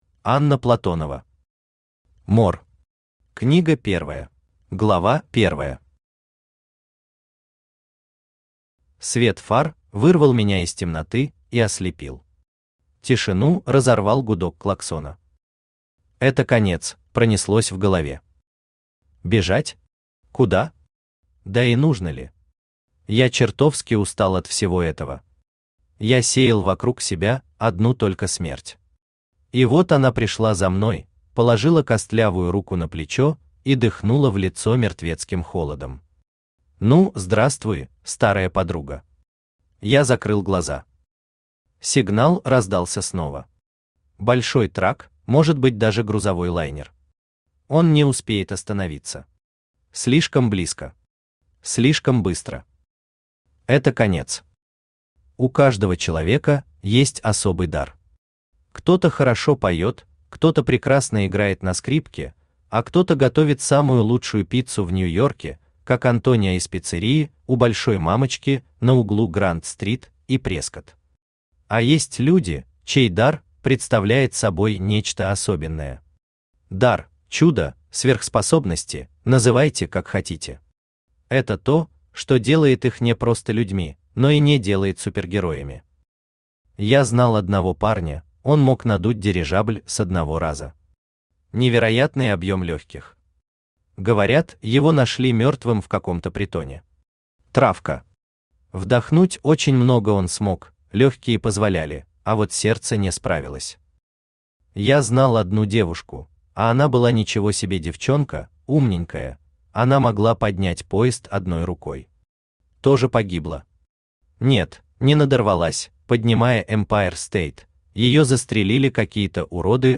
Аудиокнига Мор. Книга первая | Библиотека аудиокниг
Книга первая Автор Анна Платонова Читает аудиокнигу Авточтец ЛитРес.